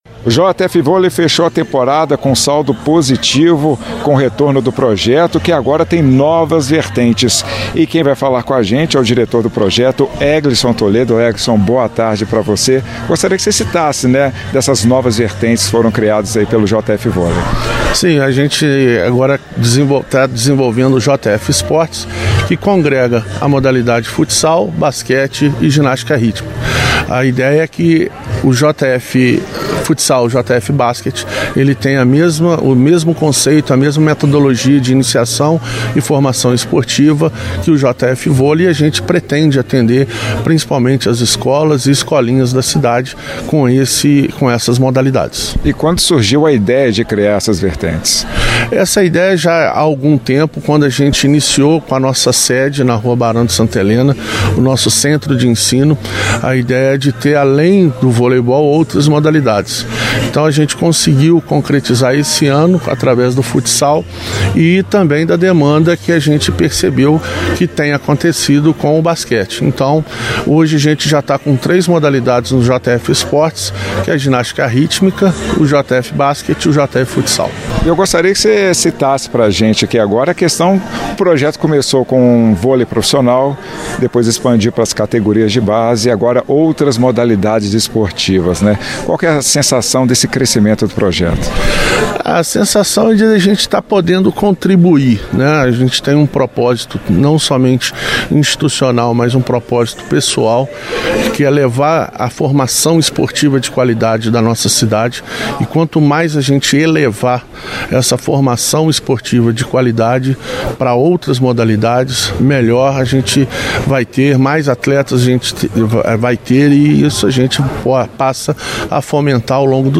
Itatiaia-Entrevista-JF-Volei.mp3